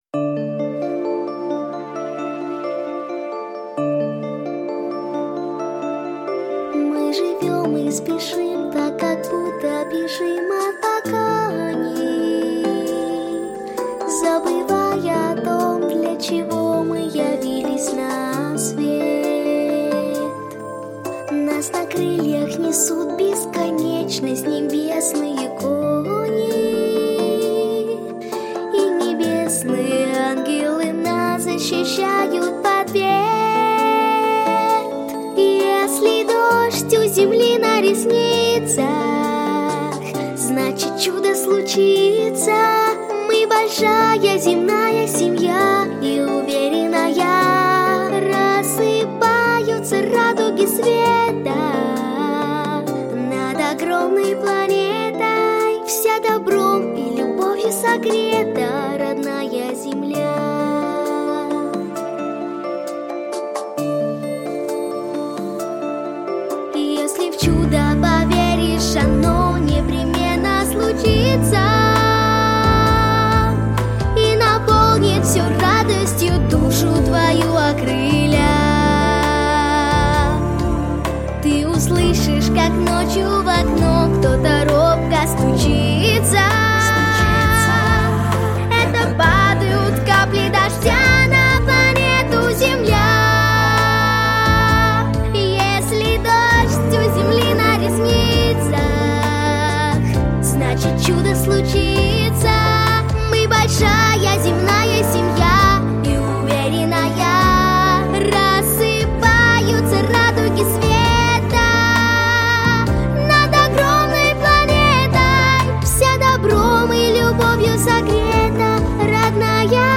• Качество: Хорошее
• Категория: Детские песни